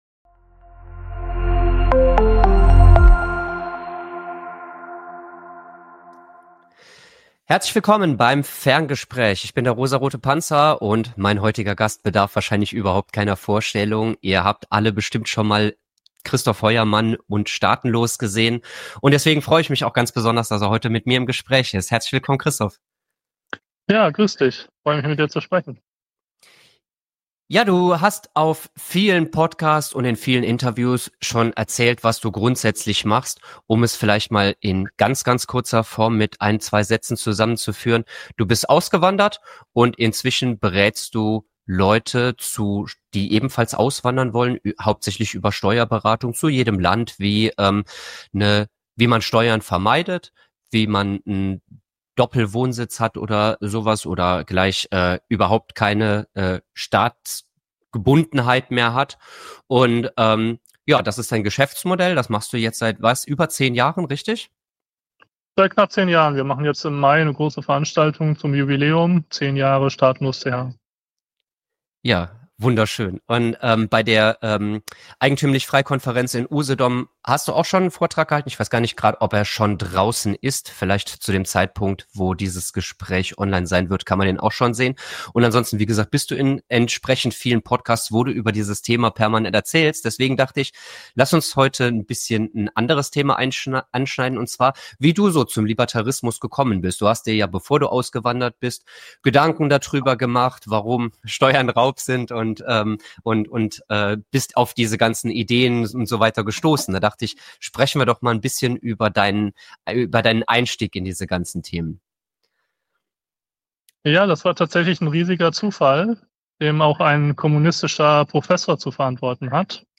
Neues Gespräch